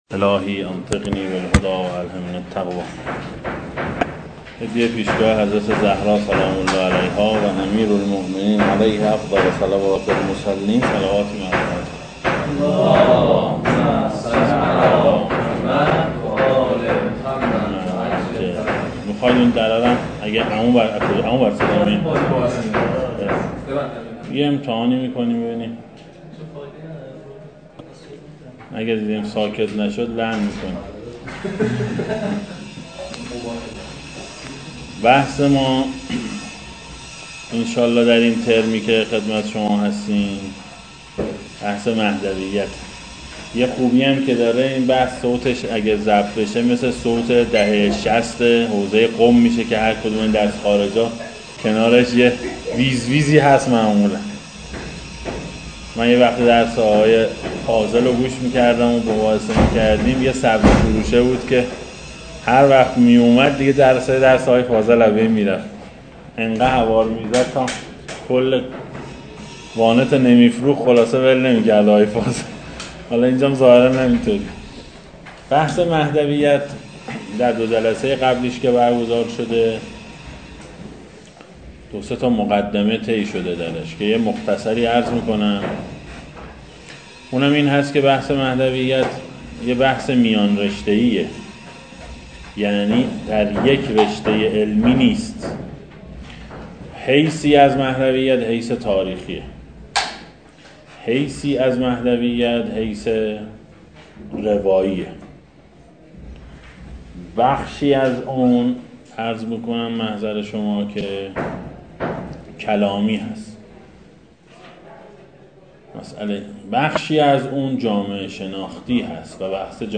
در حوزه علمیه امام خمینی (ره)